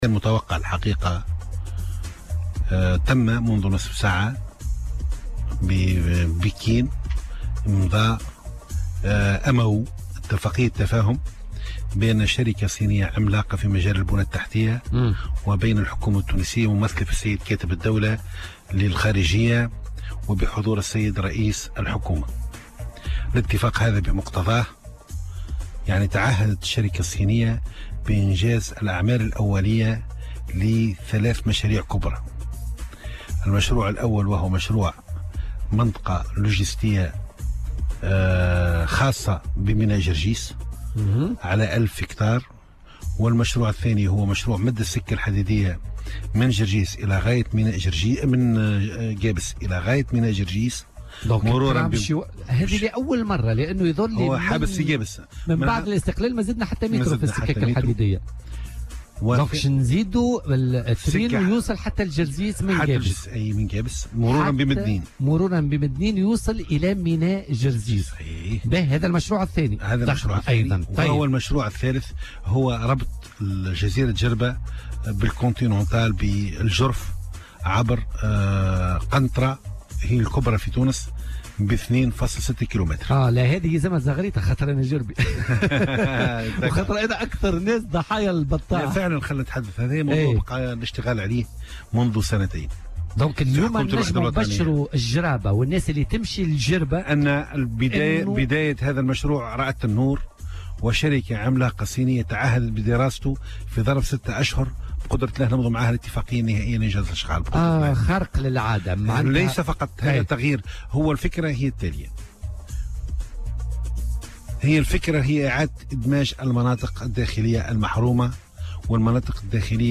وأضاف كرشيد، ضيف برنامج "بوليتيكا" أن الشركة الصينية تعهدت بإنجاز 3 مشاريع كبرى تهم إحداث منطقة لوجيستية خاصة بميناء جرجيس (تمتد على ألف هكتار) ومدّ شبكة السكك الحديدية من قابس إلى غاية ميناء جرجيس مرورا بمدنين، إضافة إلى مشروع ربط جزيرة جربة بالجرف (عبر قنطرة 2.6 كلم).